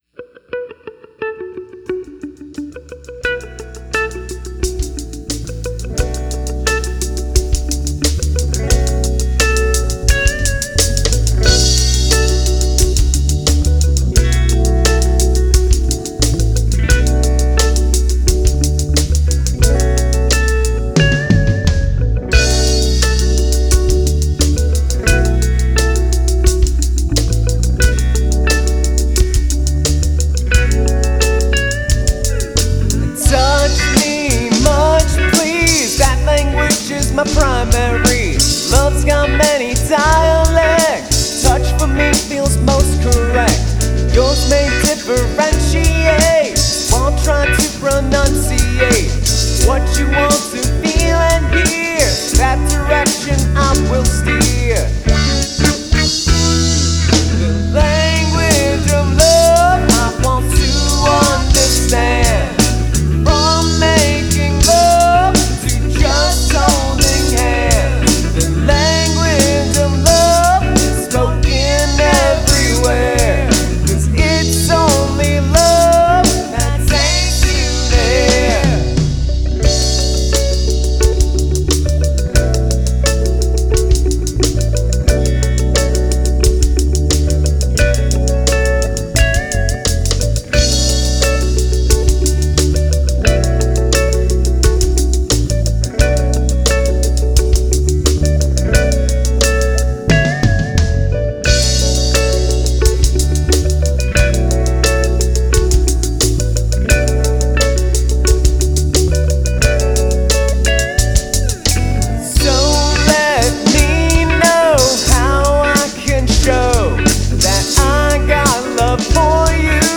chill, universal, romantic, groovy